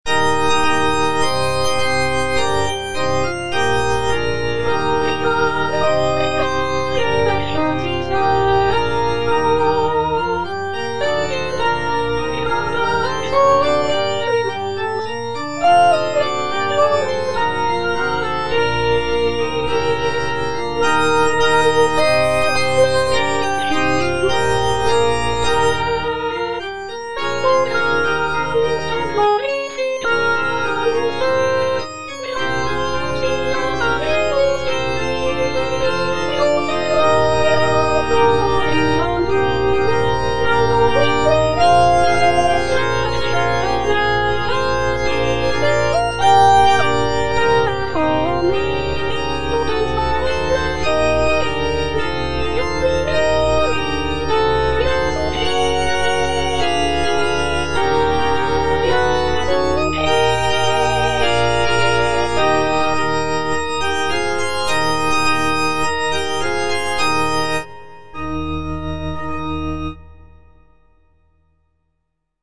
Gloria (All voices